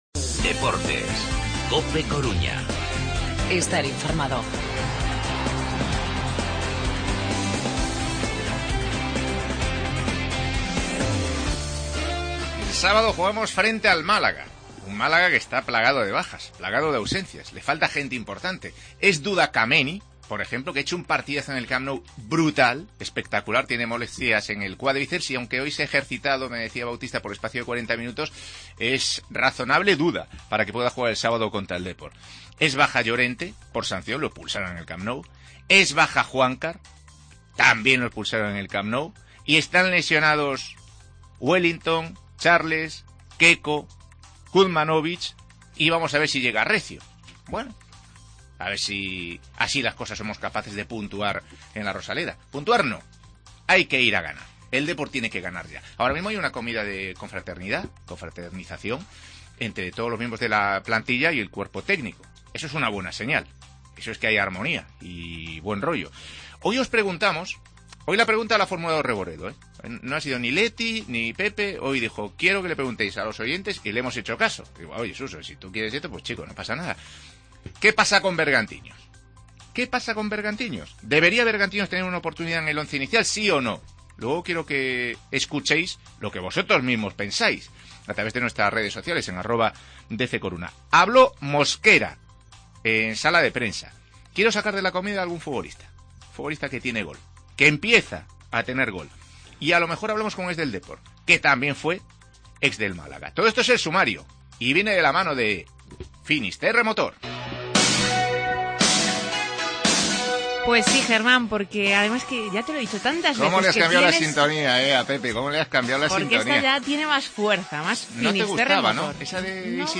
AUDIO: La enfermería del Depor, palabras de Mosquera y entrevistas a Andone y Juan Rodríguez.